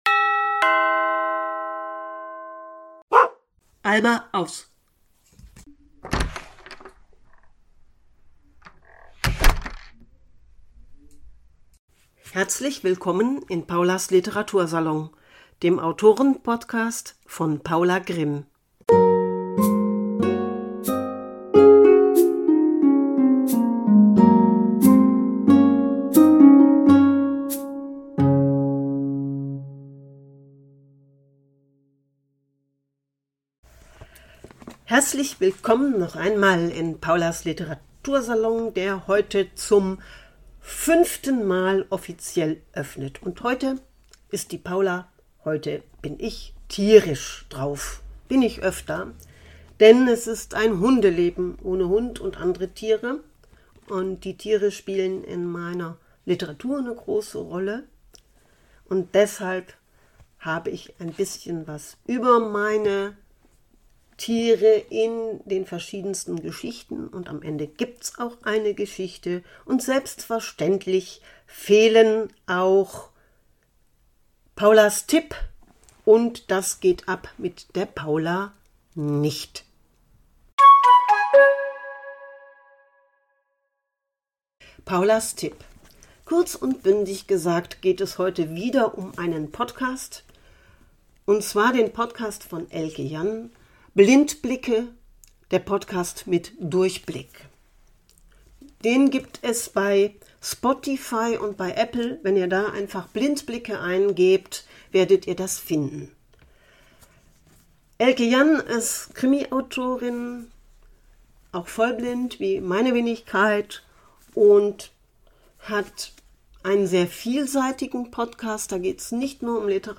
Es ist eine Spontanlesung, die ich auf Wunsch einer Bekannten bereits vor mehreren Jahren gelesen habe. - Vorsicht, nicht perfekt.